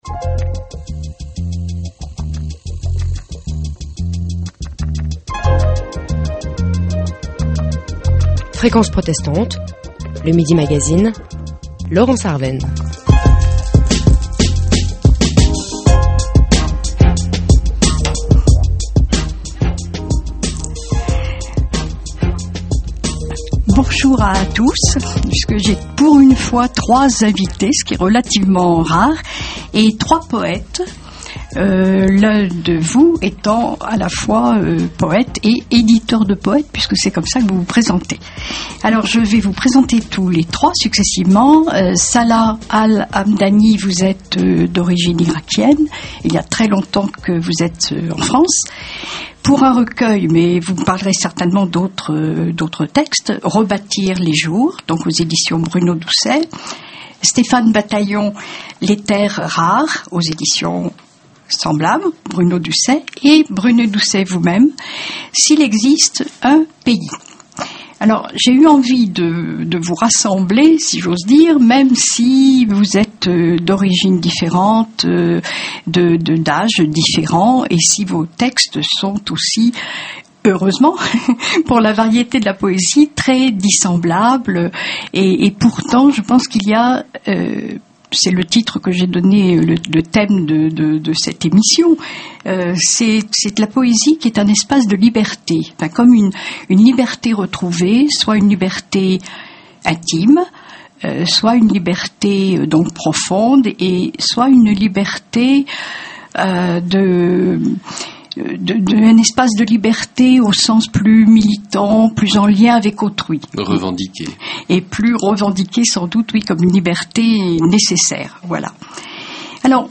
Invité de l’émission Midi Magazine de Fréquence Protestante